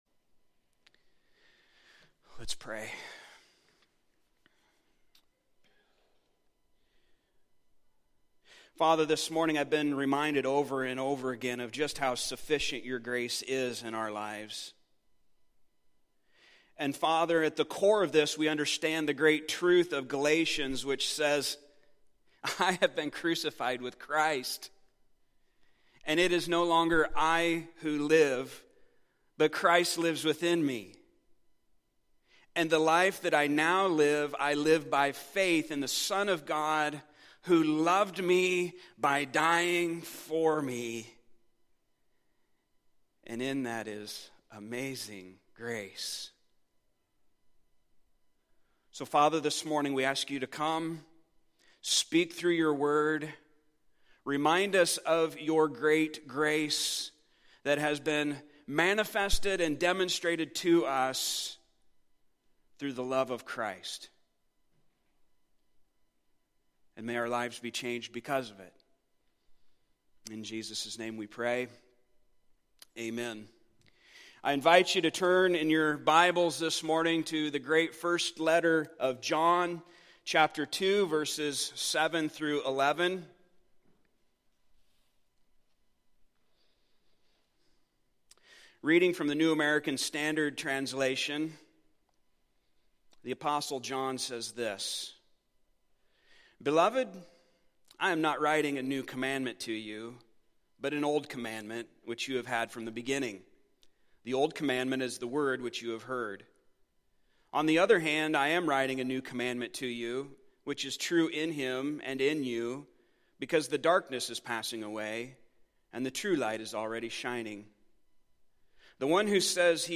Passage: 1 John 2:7-11 Service Type: Sunday Morning